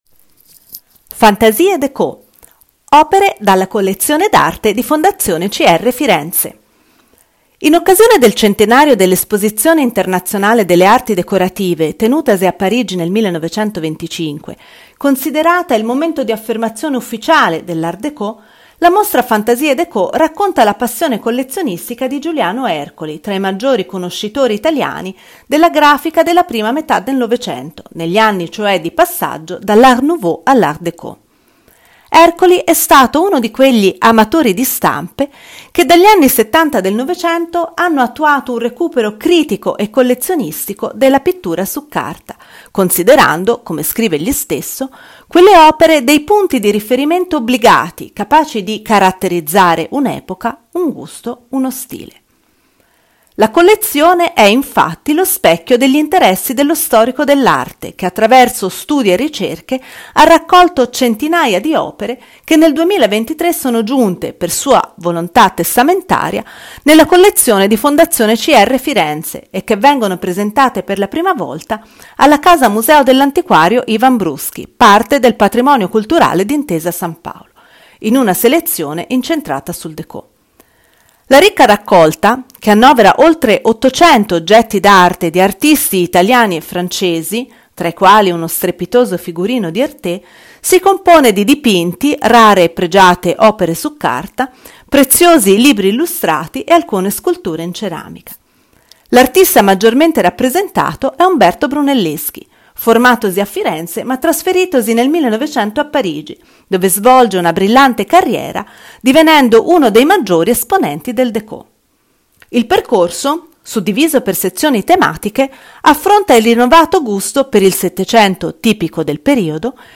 AUDIOGUIDA MOSTRA